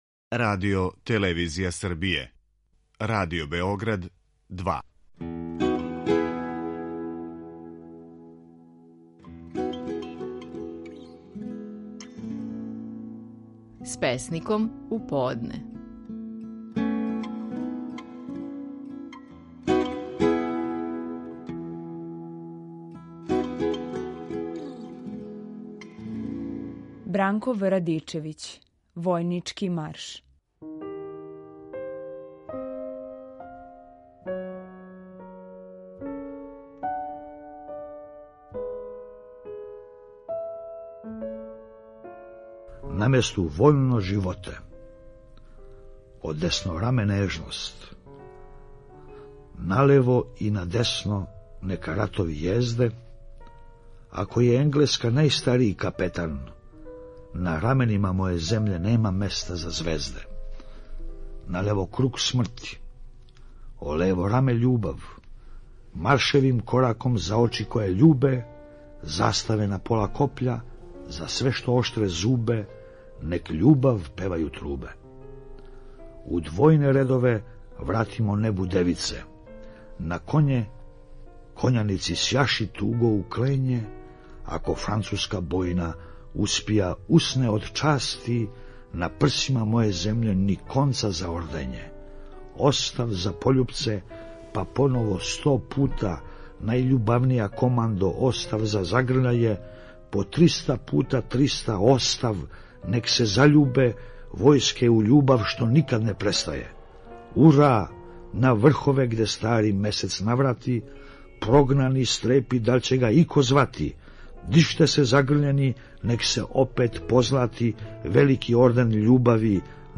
Стихови наших најпознатијих песника, у интерпретацији аутора.
Бранко В. Радичевић говори своју песму „Војнички марш".